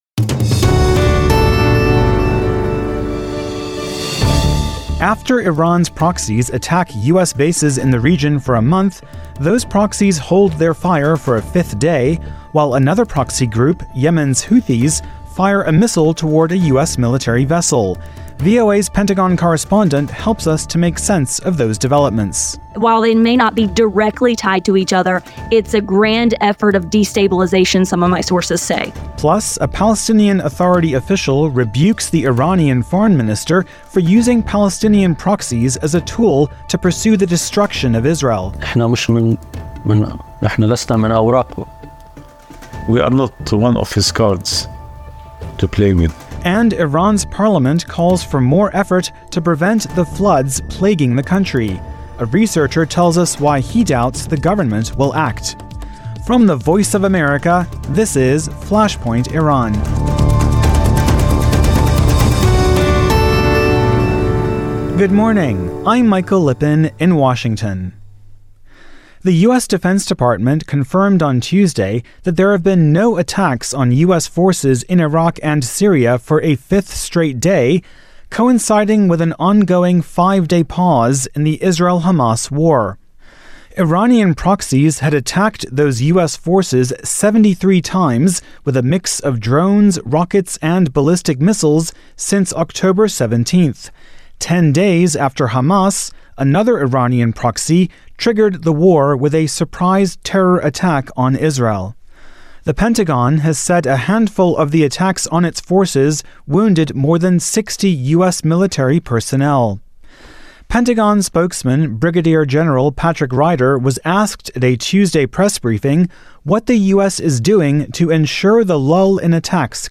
U.S. Defense Department Press Secretary Patrick Ryder on Iranian proxies’ lull in attacks on U.S. forces in Iraq and Syria.
Palestinian Authority Social Development Minister Ahmed Majdalani on Iran using its Palestinian proxies as a tool to seek the destruction of Israel.